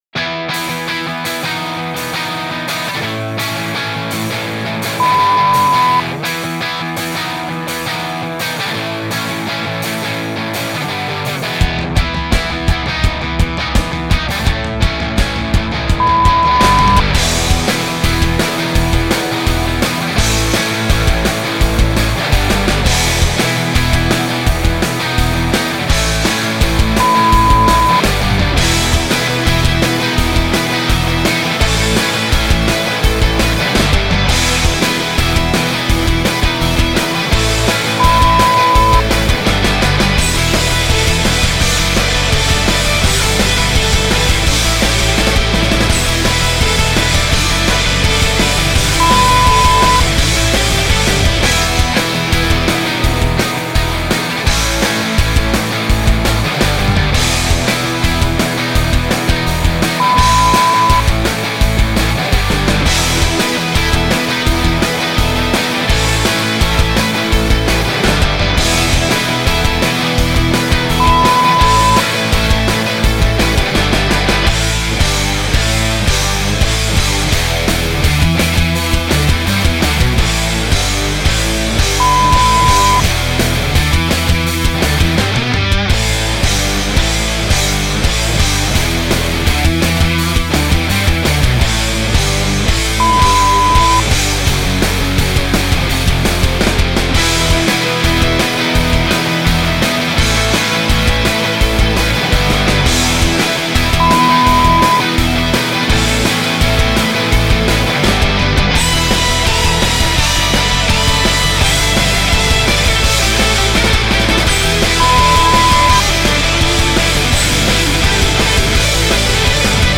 soft and sweet sounds of Afrobeats
Nigerian R&B
uptempo Pop infused sounds